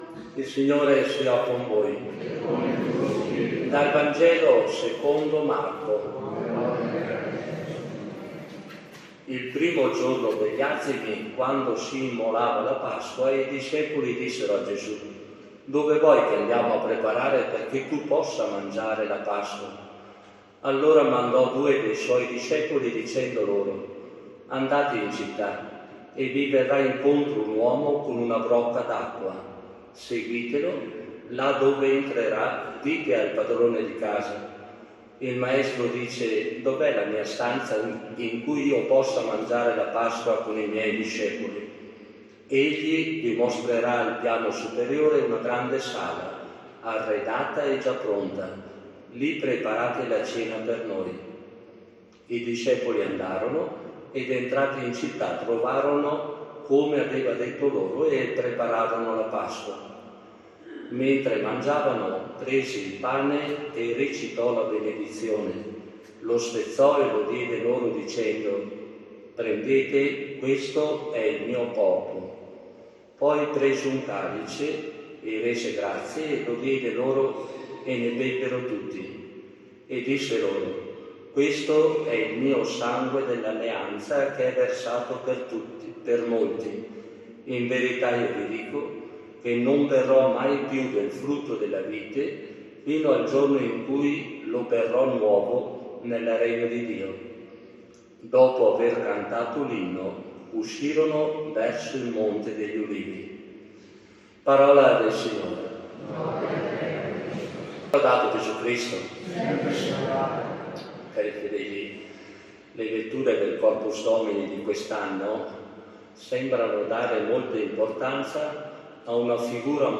SFM-CD-Omelia-2---6----24.mp3